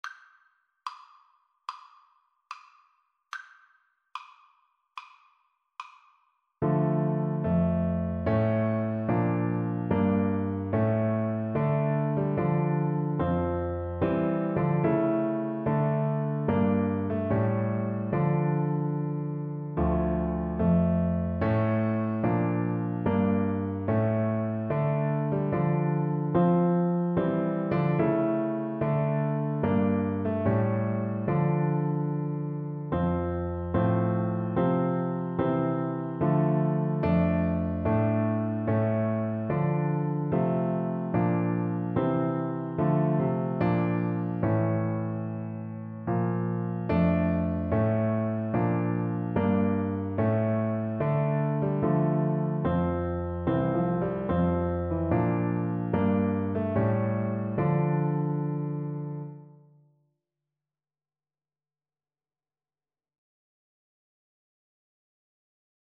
4/4 (View more 4/4 Music)
D5-Bb5
Classical (View more Classical Trumpet Music)